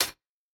UHH_ElectroHatA_Hit-27.wav